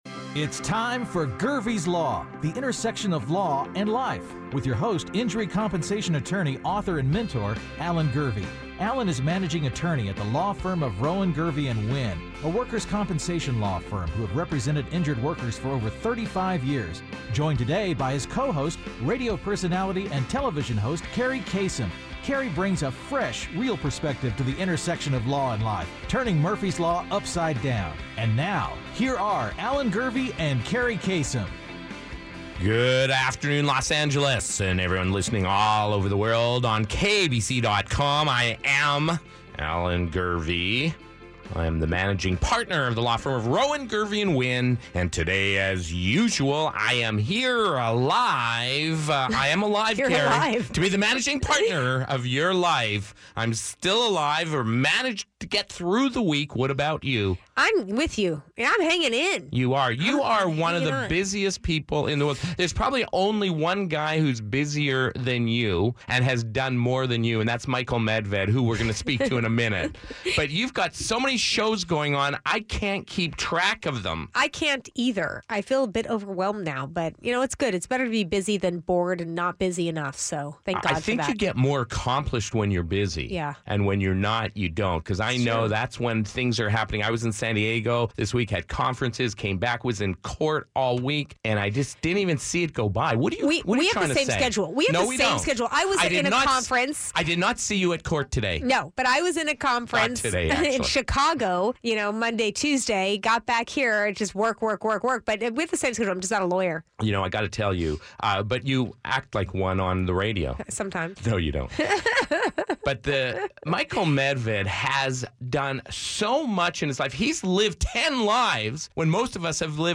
Hear his views on just about everything in this no holds barred one on one interview!